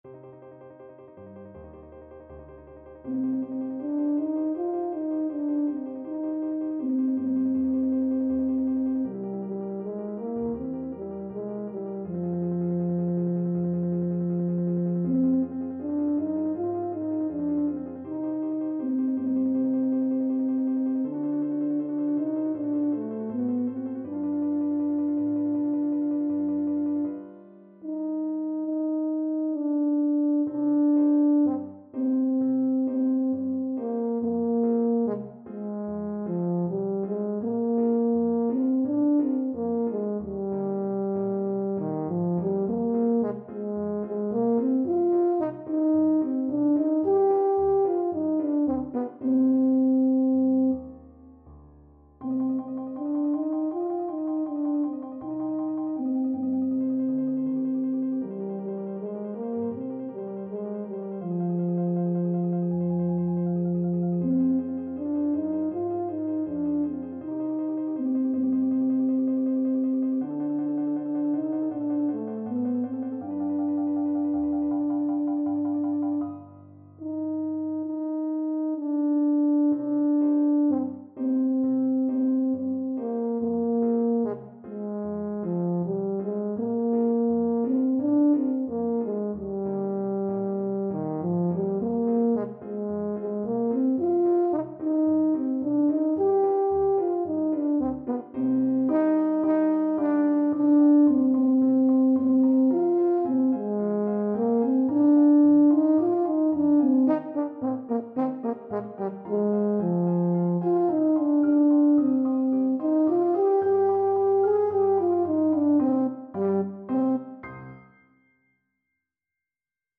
Moderately slow =c.80
4/4 (View more 4/4 Music)
Traditional (View more Traditional Tenor Horn Music)
world (View more world Tenor Horn Music)